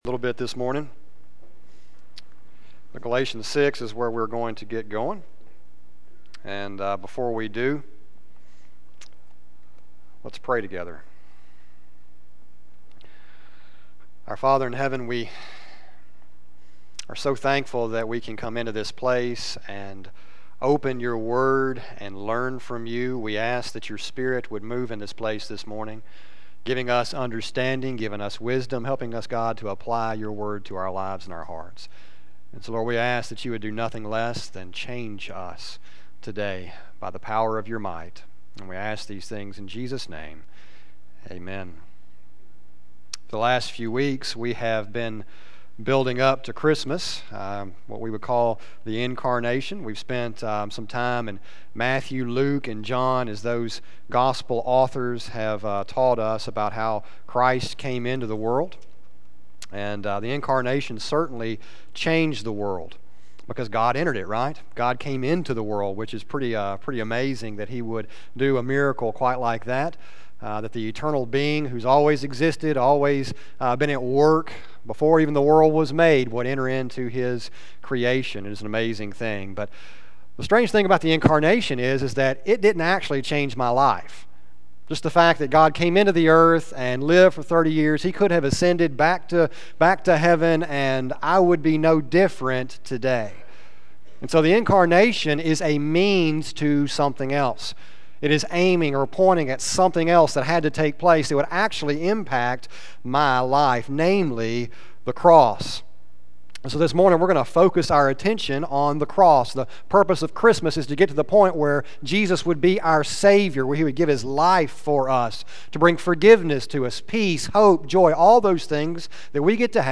sermon122715a.mp3